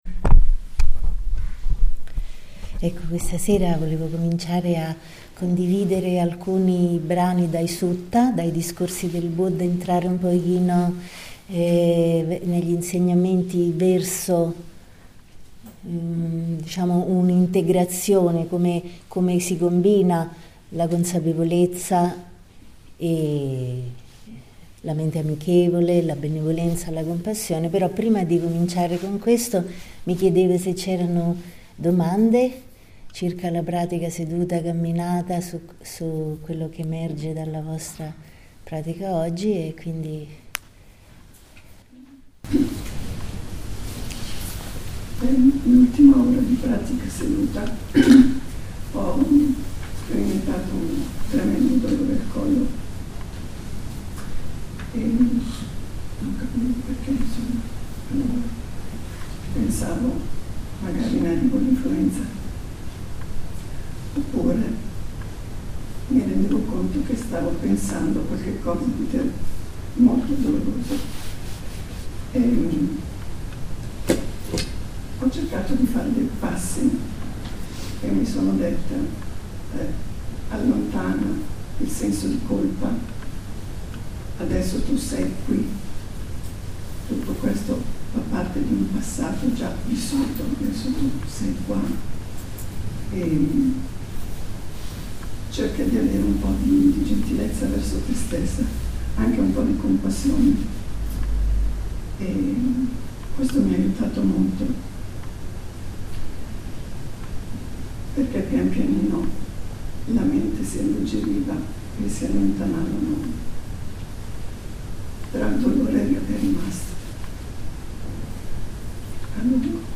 Metta come relazione (d&r, discorso, parabola degli acrobati: Sedaka Sutta, SN 47.19)